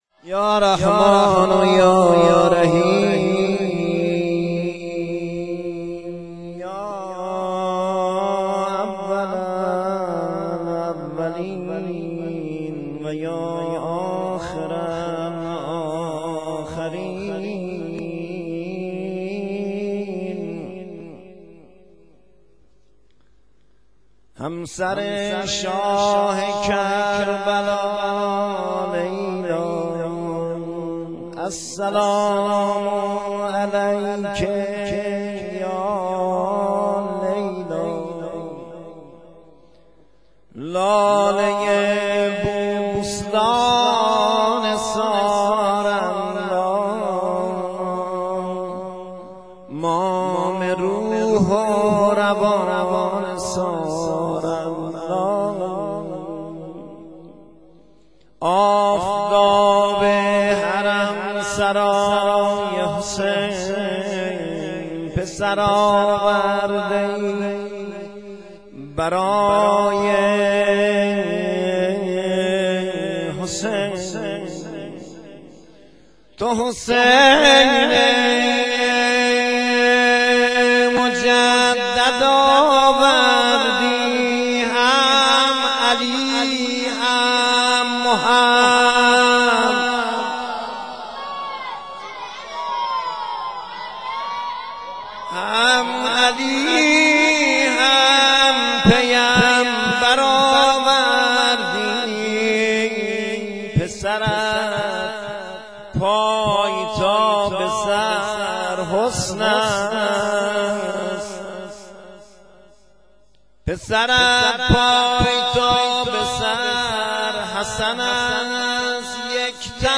مدیحه سرایی
شام میلاد حضرت علی اکبر 1392